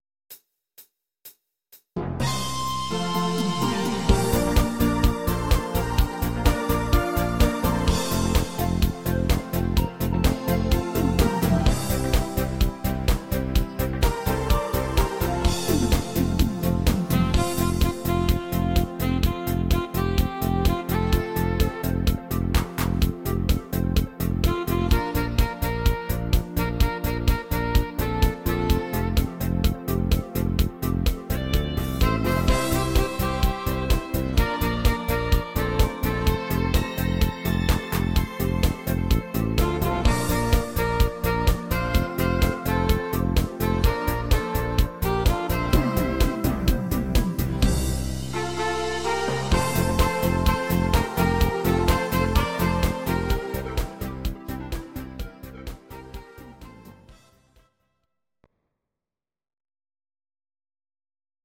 Audio Recordings based on Midi-files
Pop, German, 2010s